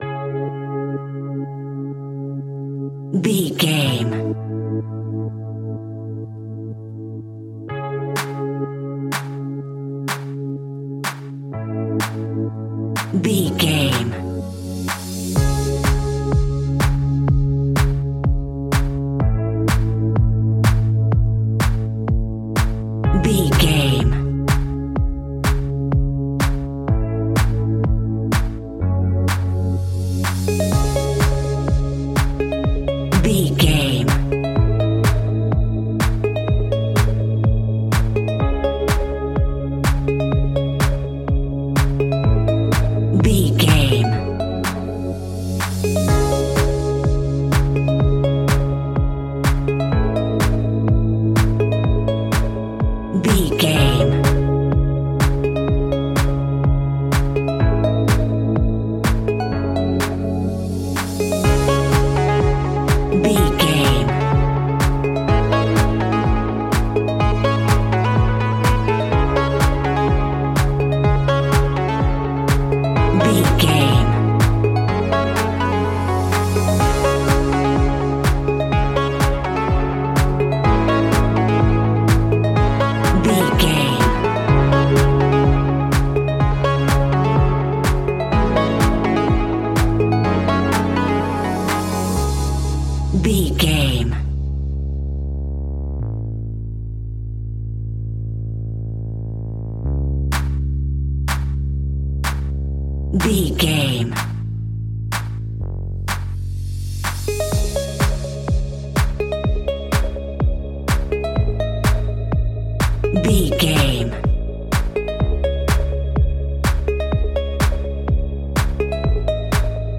Aeolian/Minor
C#
groovy
hypnotic
dreamy
smooth
synthesiser
drum machine
funky house
deep house
nu disco
upbeat
funky guitar
wah clavinet
synth bass
funky bass